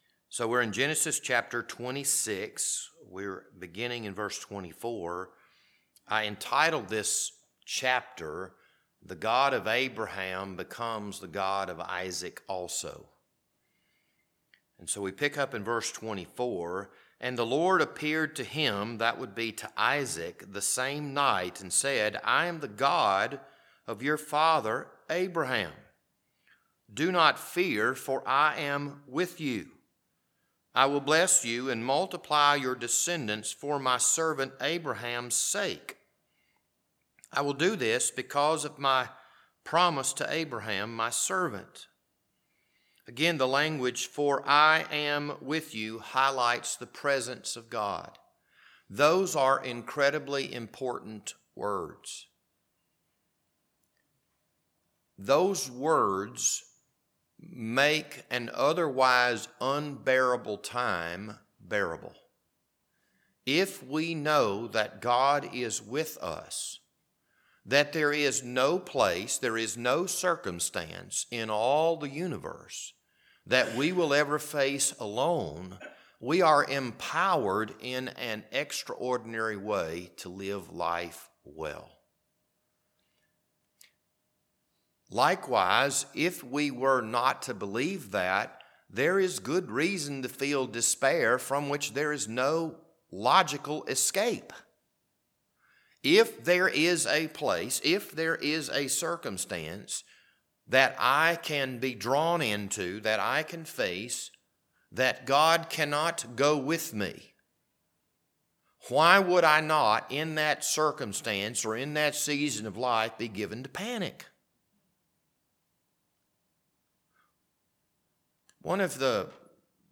This Wednesday evening Bible study was recorded on June 14th, 2023.